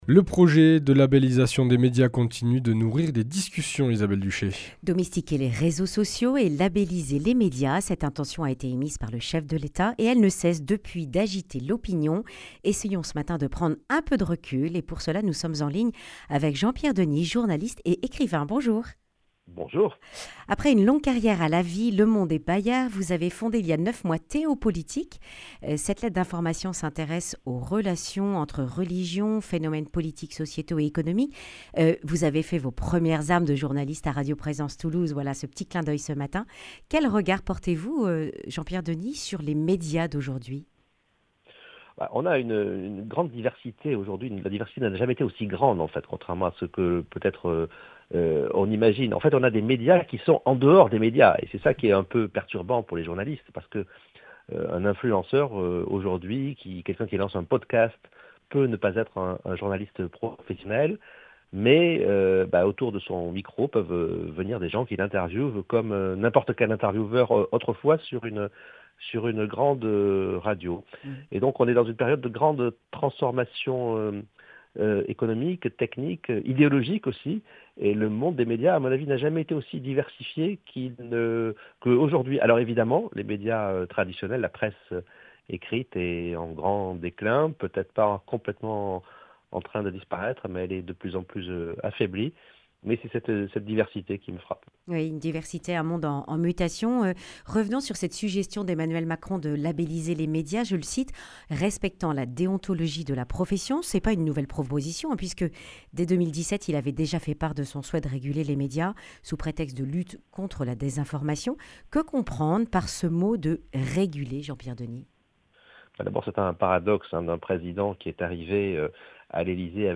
Accueil \ Emissions \ Information \ Régionale \ Le grand entretien \ Labelliser les médias, une bonne idée ?